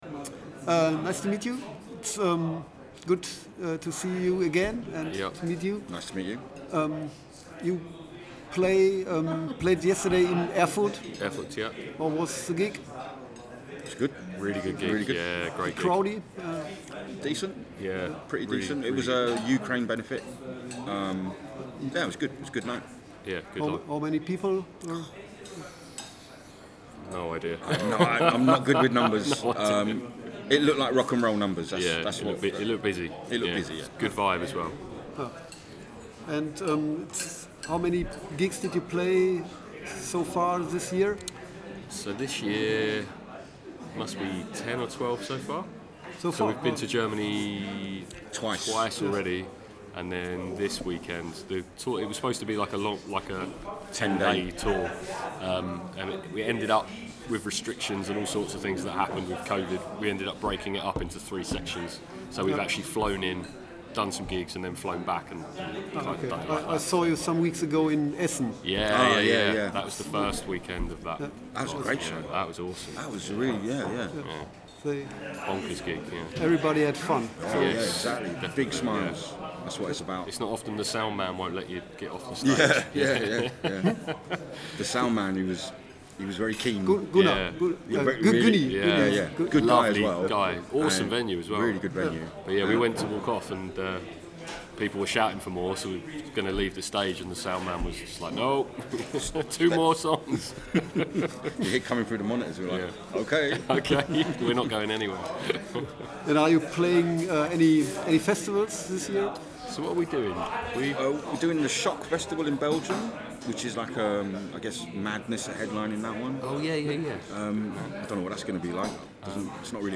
interview-with-snuff-oxfest-2022-in-duesseldorf-mmp.mp3